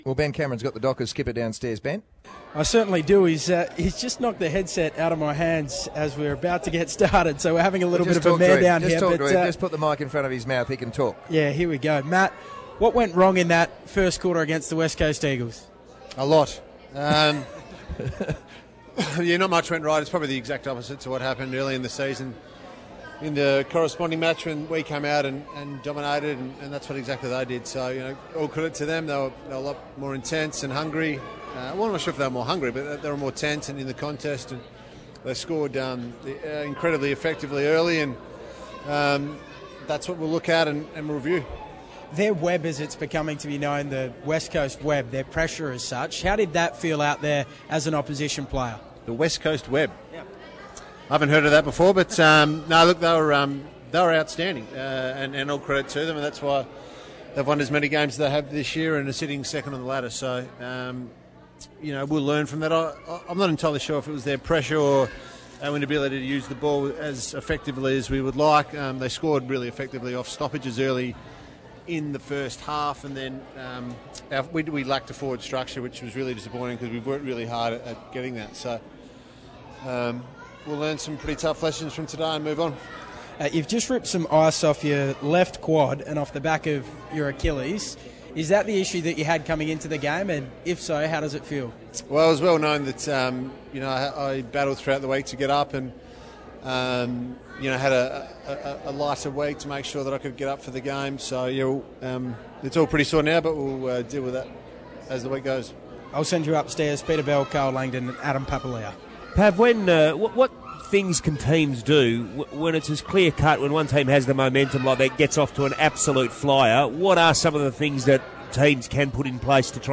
Matthew Pavlich Post Game Interview